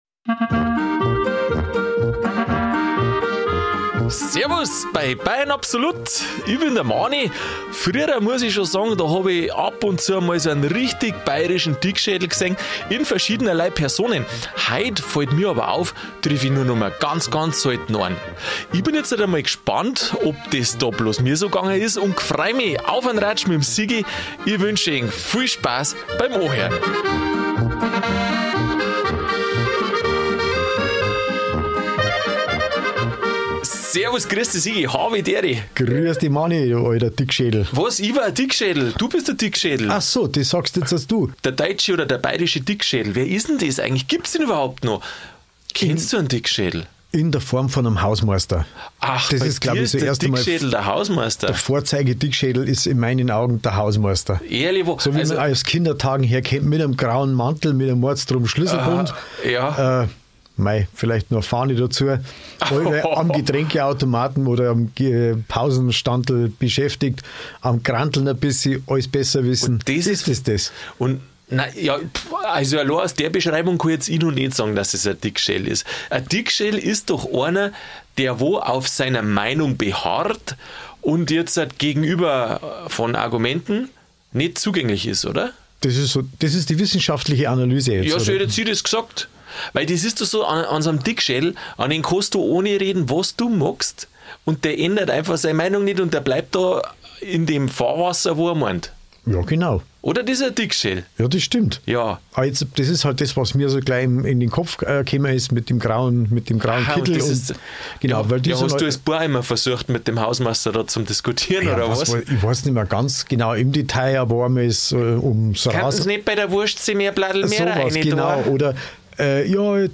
Gibt es ihn noch, den bayerischen Dickschädel? Wir gehen ihm bei einem gemütlichen Ratsch auf die Spur.